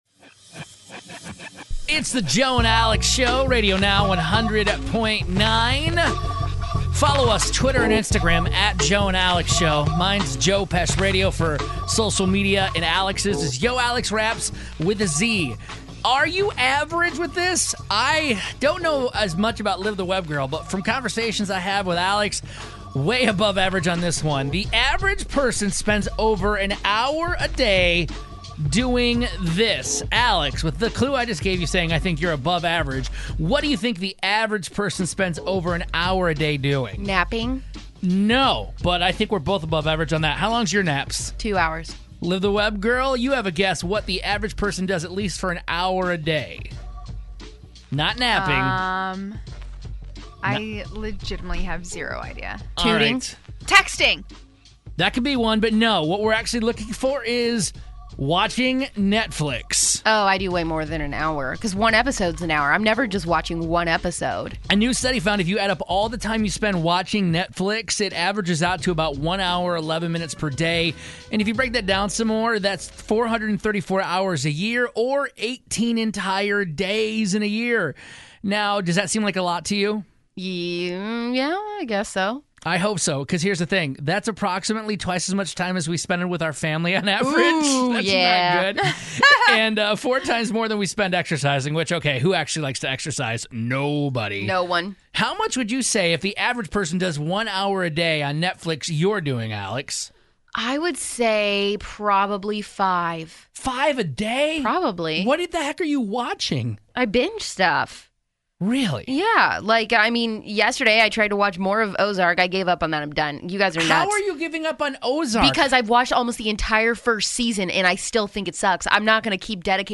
A Netflix discussion that started out innocent ended in an argument